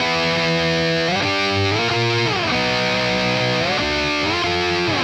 AM_RawkGuitar_95-E.wav